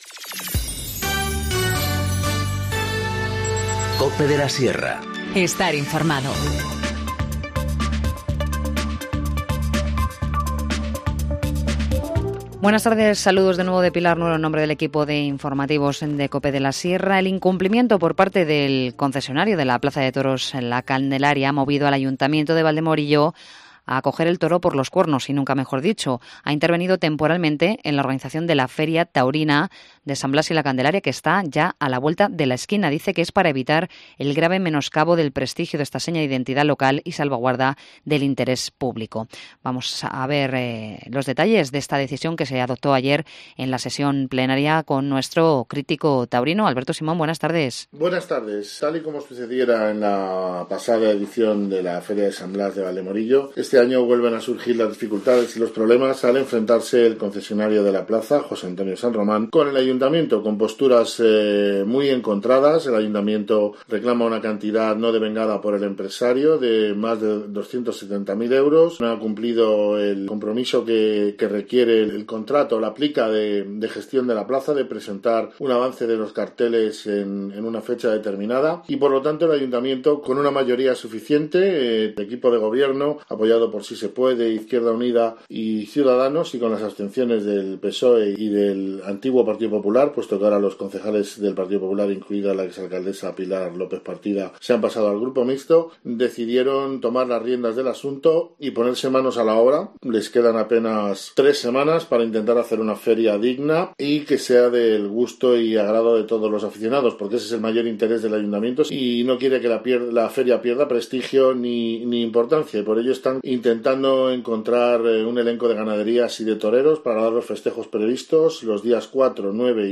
Informativo Mediodía 18 enero-14:50H